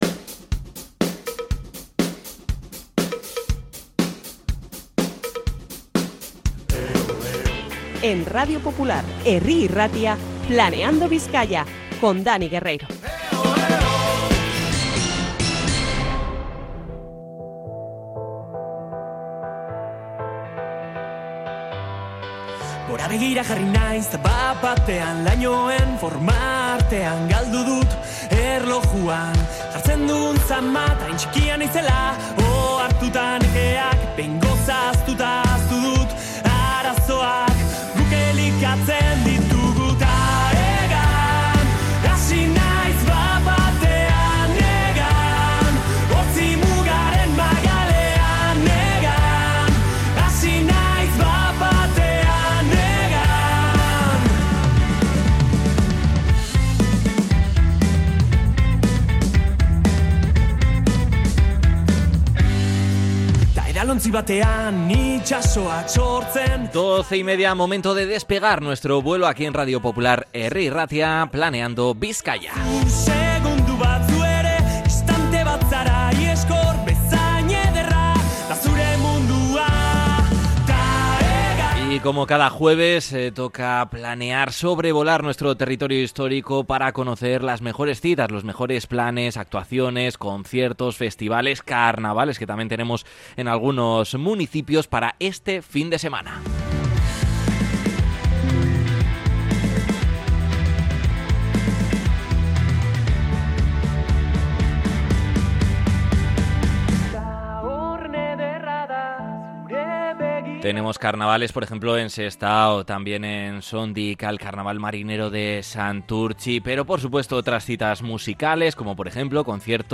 Hablamos con el polifacético actor y cómico que actúa este sábado en el Teatro Campos con su show 'Prohibido echarle cacahuetes al mono'